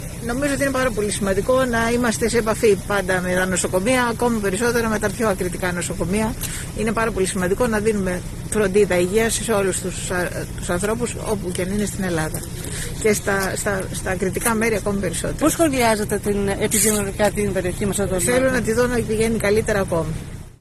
“Θέλω να δω να πηγαίνουν ακόμη καλύτερα τα πράγματα στην περιοχή”, δήλωσε το απόγευμα της Τετάρτης από το Διδυμότειχο η αναπληρωτής υπουργός Υγείας, Μίνα Γκάγκα, προσερχόμενη στο Νοσοκομείο.
υπουργός-Υγείας-Μίνα-Γκάγκα.mp3